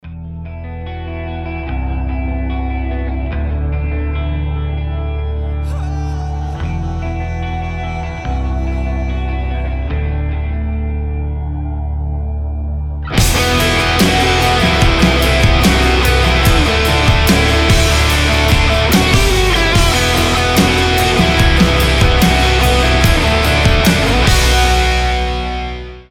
• Качество: 320, Stereo
без слов
нарастающие
электрогитара
Alternative Rock
крик